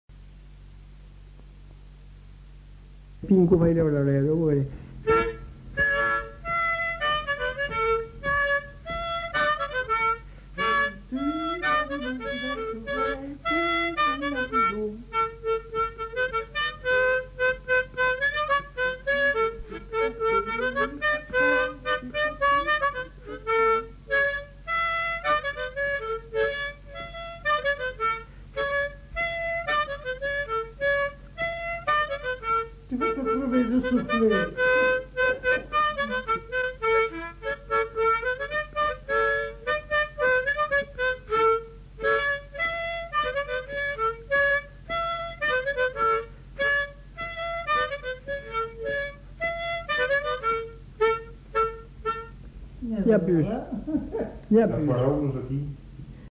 Aire culturelle : Haut-Agenais
Genre : morceau instrumental
Instrument de musique : accordéon chromatique
Danse : polka piquée
Ecouter-voir : archives sonores en ligne